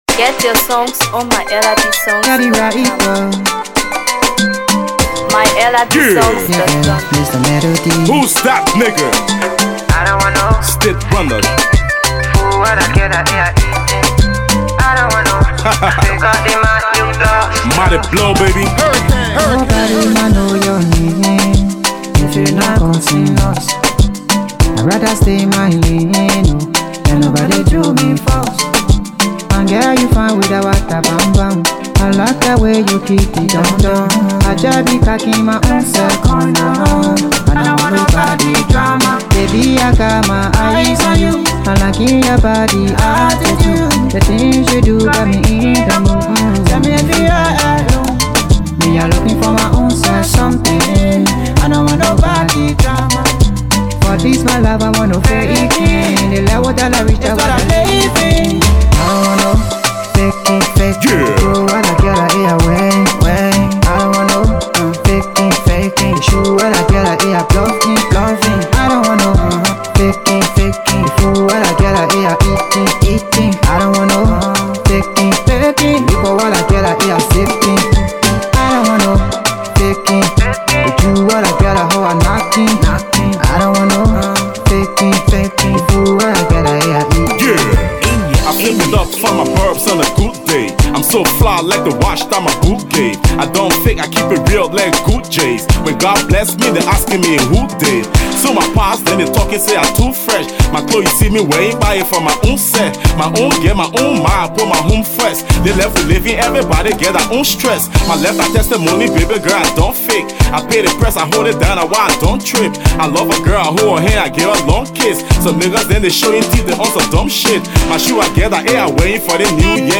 Afro PopHipco
soulful delivery
fiery verses